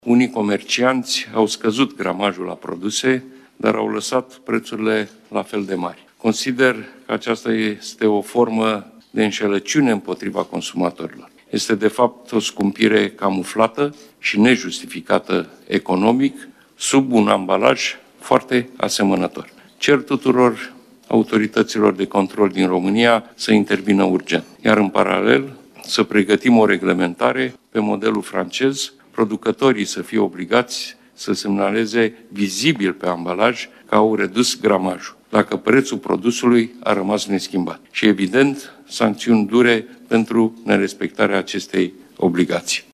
Premierul a afirmat că fenomenul este tot mai întâlnit, şi în statele europene:
insert-Ciolacu-10-05-gramaj-mp3.mp3